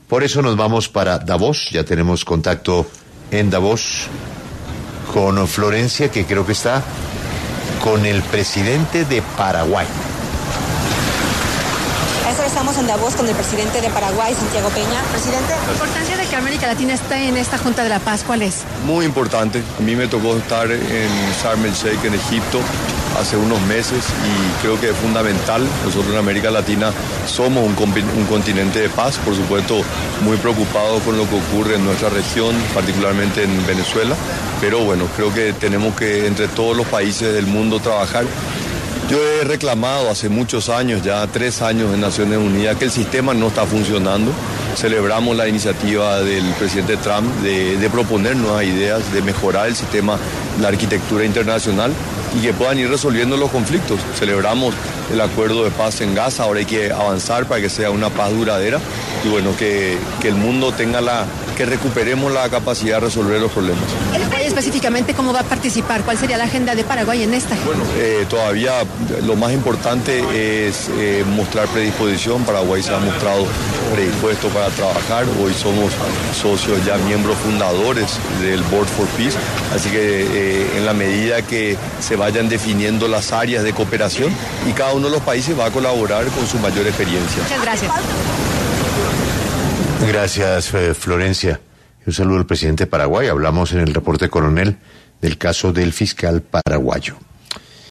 Al respecto, 6AM W, de Caracol Radio, conversó con Santiago Peña, jefe de Estado paraguayo, quien se refirió a la creación de esta Junta de Paz.